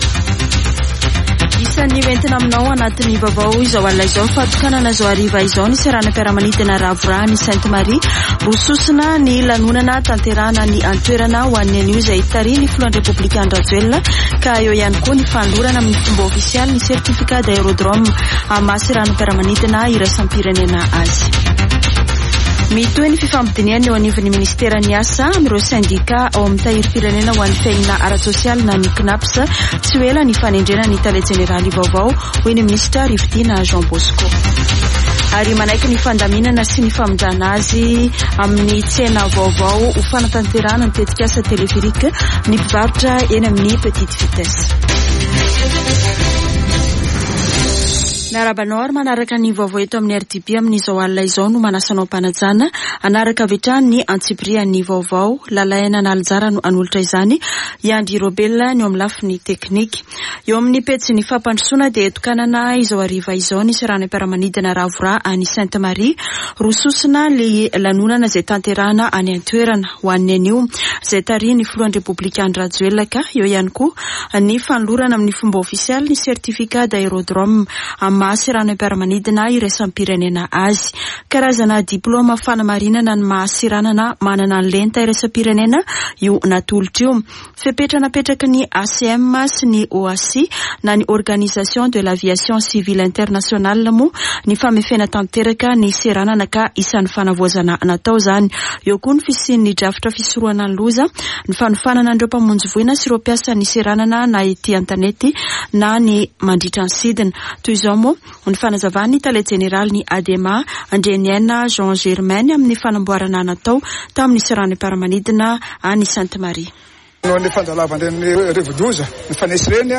[Vaovao hariva] Zoma 14 jolay 2023